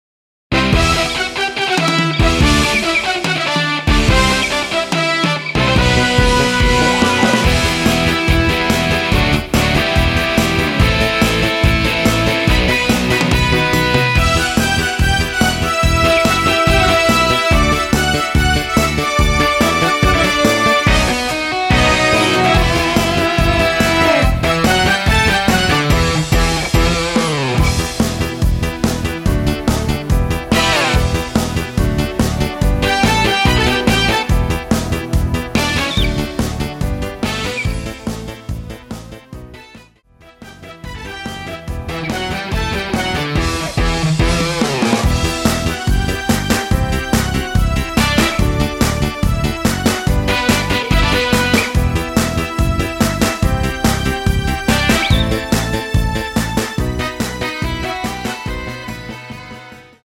원키 멜로디 포함된 MR 입니다.(미리듣기 참조)
Em
앞부분30초, 뒷부분30초씩 편집해서 올려 드리고 있습니다.
중간에 음이 끈어지고 다시 나오는 이유는